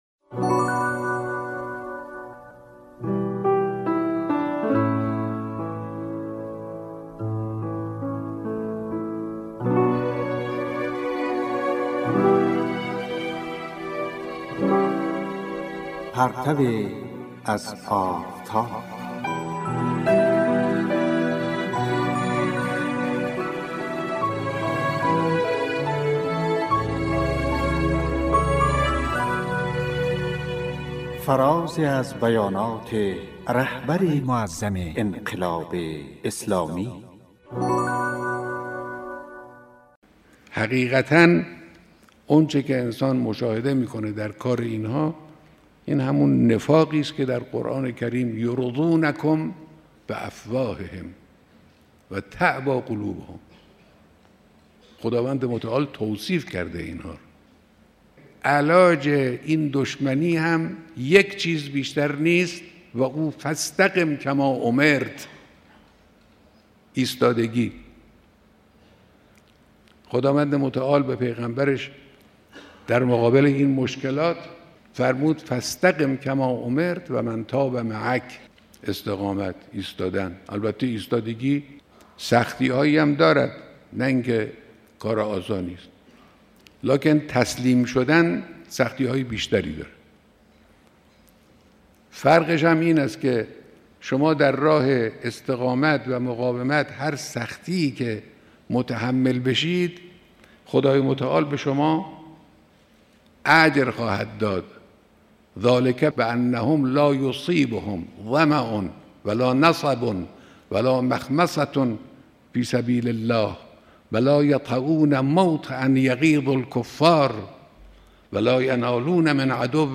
"پرتویی از آفتاب" کاری از گروه معارف رادیو تاجیکی صدای خراسان است که به گزیده ای از بیانات رهبر معظم انقلاب می پردازد.